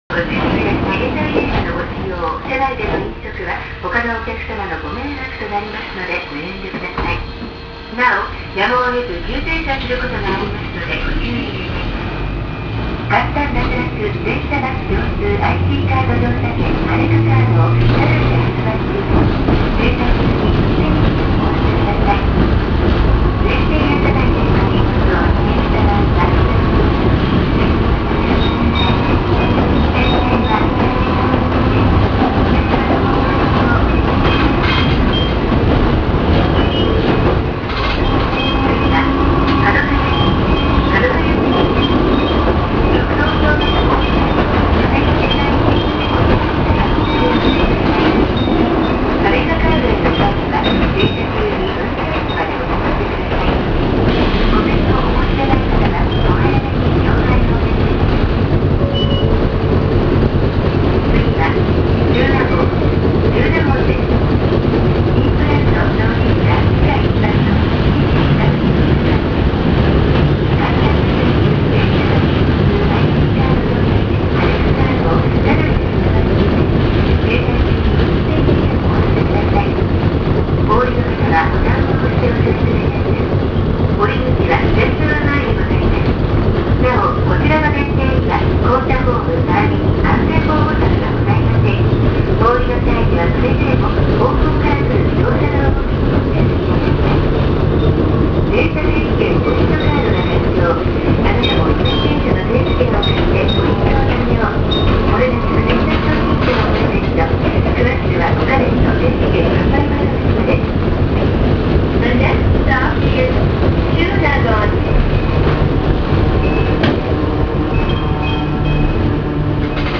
〜車両の音〜
・7000形走行音
【東山線】東山〜中納言（2分15秒：734KB）…7302にて
路面電車によくある「旧型車の走行機器を流用して車体を新製した車両」なので、吊り掛け式です。7302号車はこの時、真庭ﾎﾀﾙ鑑賞のＰＲ電車に改装されており、車内に風鈴がいくつかかかっていたので、その音が入ってます。涼しげでいいですね。